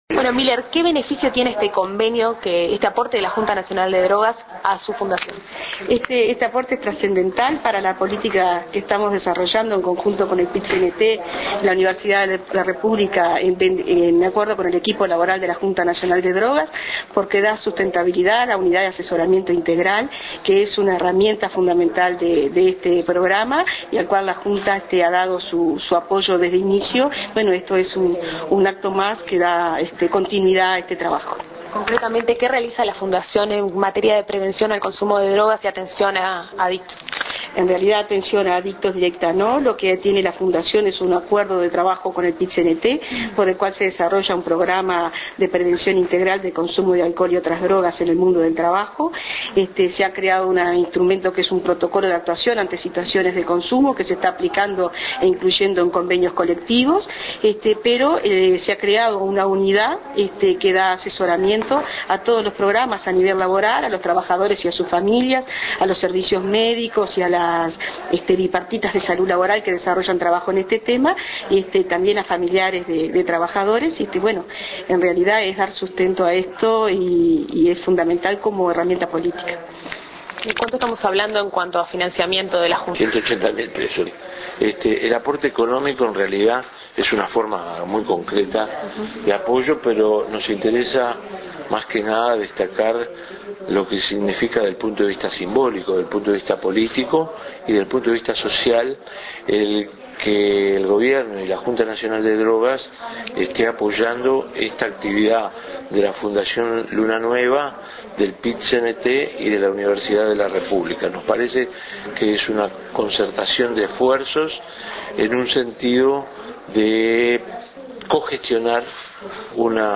Palabras del Secretario General de la JND, Milton Romani, en la firma del Convenio entre la JND y la Fundación Luna Nueva.